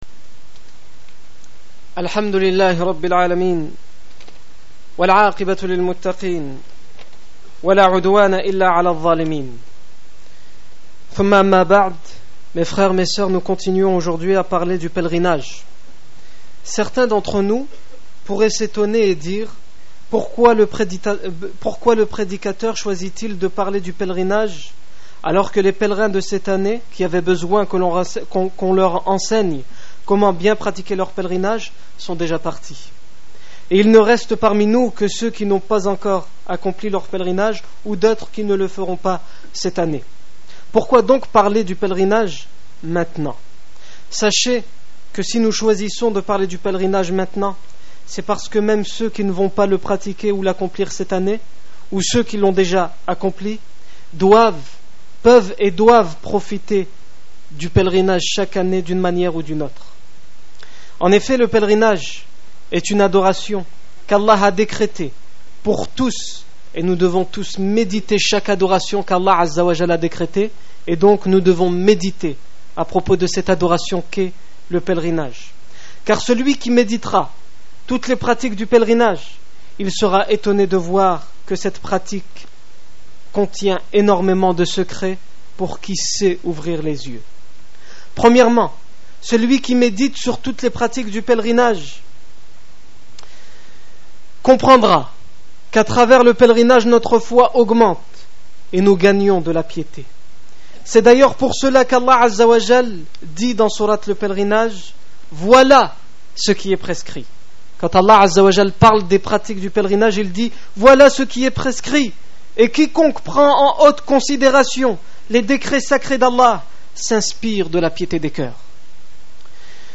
Discours du 28 novembre 2008
Discours du vendredi